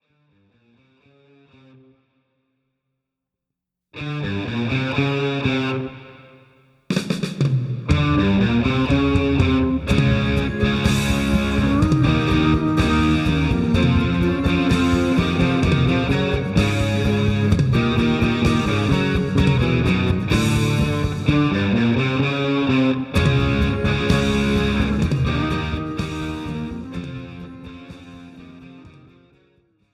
Without Backing Vocals. Professional Karaoke Backing Tracks.
Folk , Rock